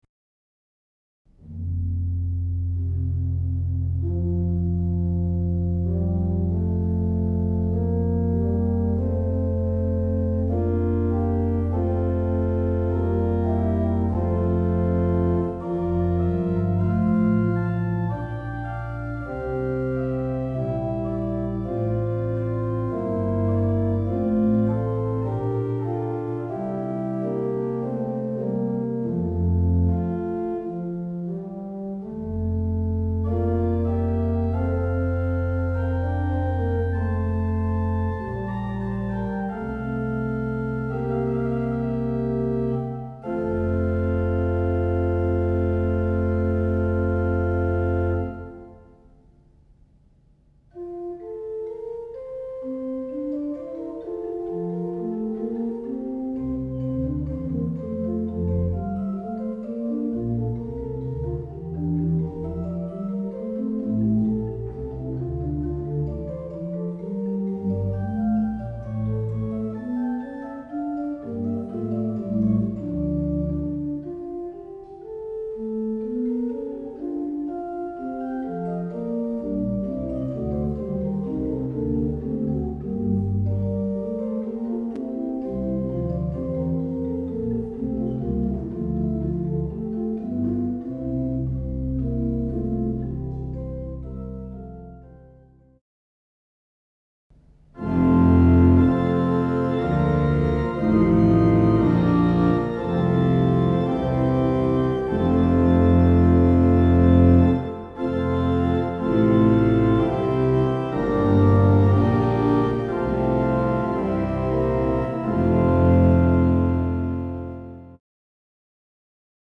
Vaikka se on vain yksisormioinen, se soi moni-ilmeisesti.